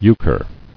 [eu·chre]